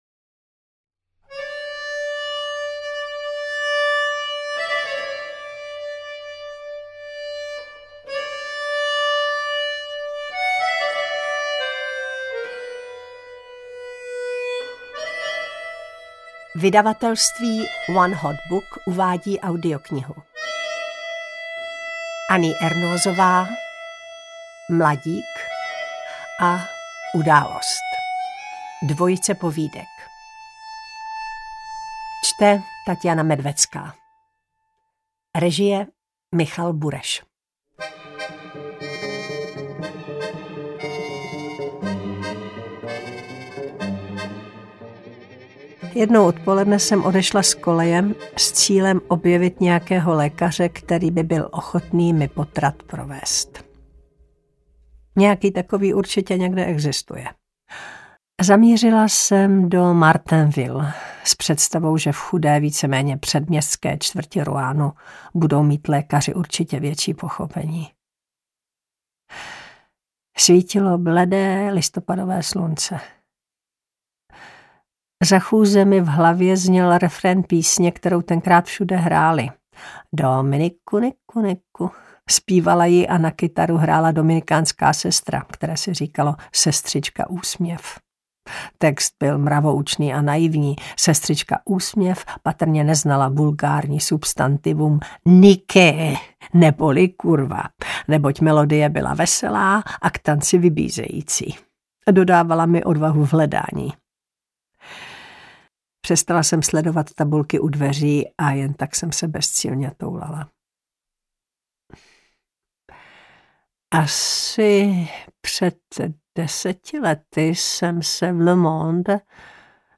Audiobook
Audiobooks » Short Stories
Read: Taťjana Medvecká